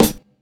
CT_SNR.wav